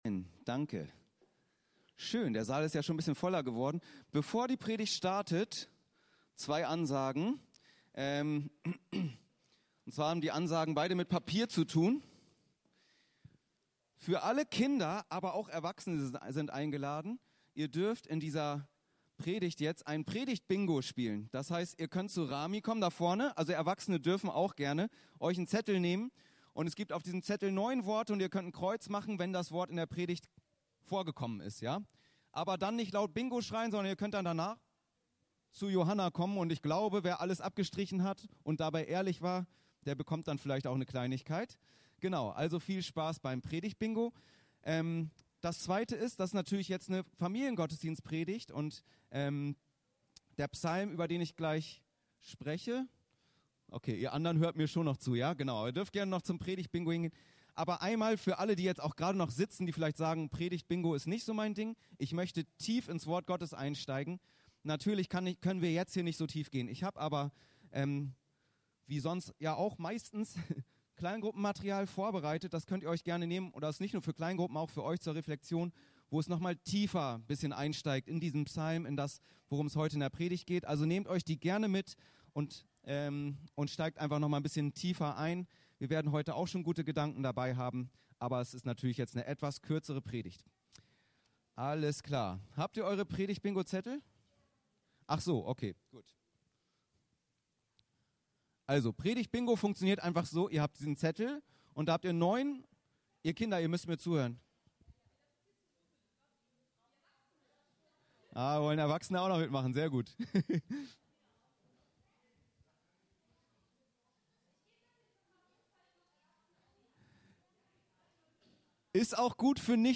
Predigten und Lehre aus der Anskar-Kirche Hamburg-Mitte